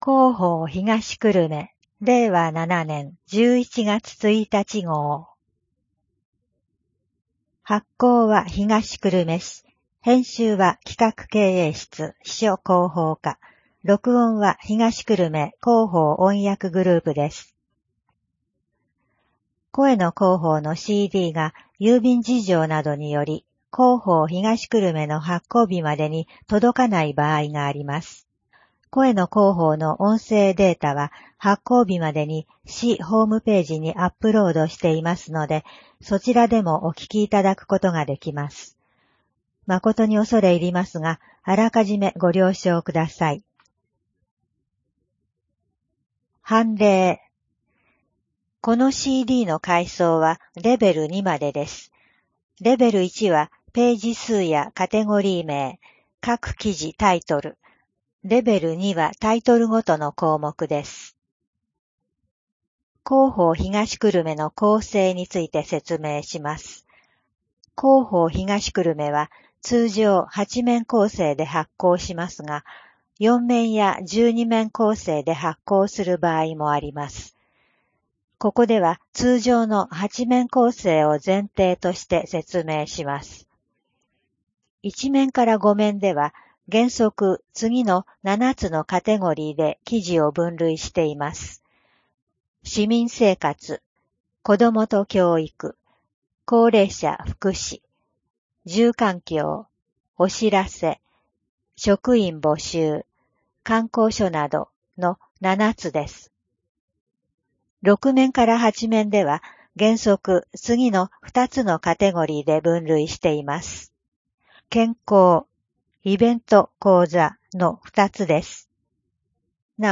声の広報（令和7年11月1日号）